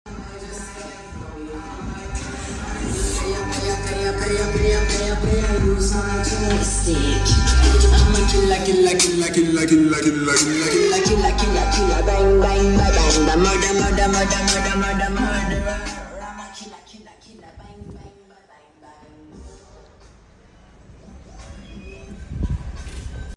Cızırtı deil net ses apocalypse sound effects free download